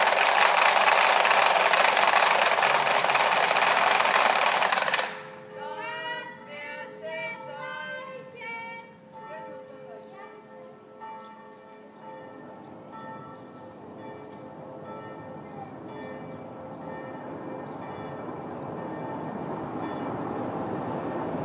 Karfreitagsratschen um 14 Uhr